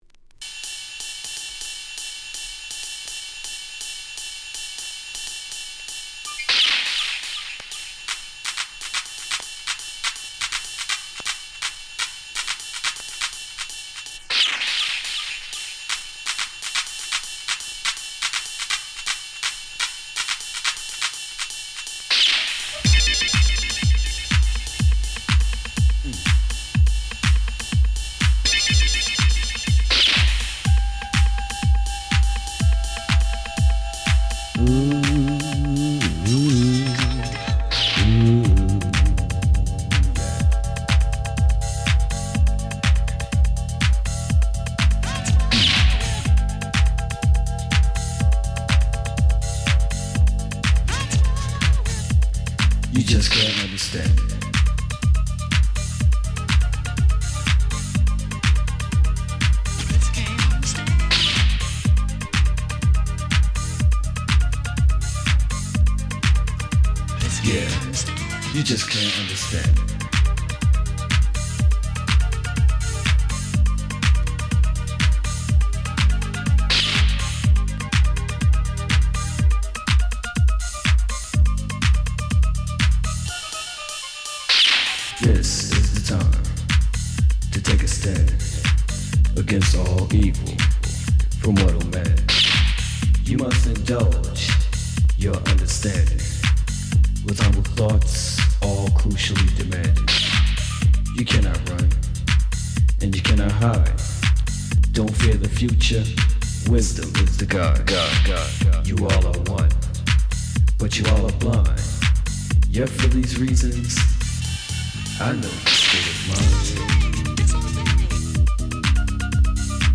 Genre: Deep House.